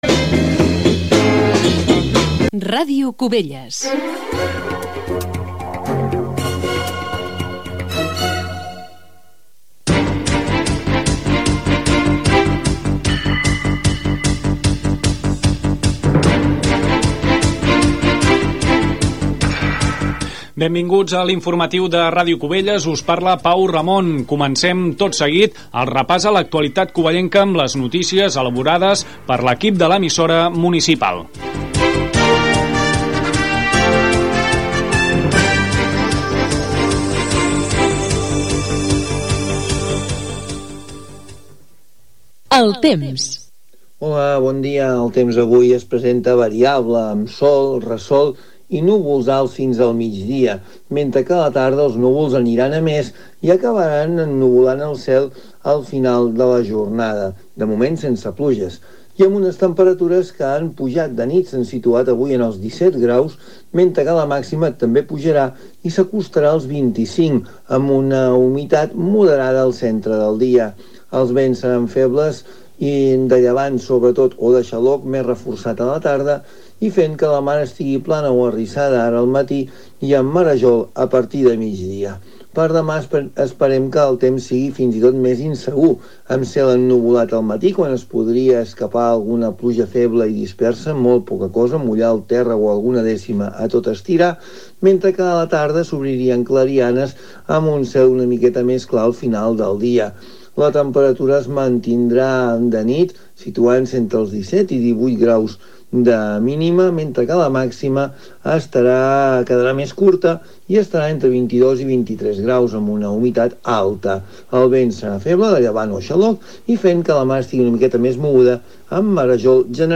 Indicatiu de l'emissora, careta, presentació, el temps, indicatiu, el servei d'atenció primària i la lluita contra el tabaquisme en el Dia Mundial sense tabac.
Informatiu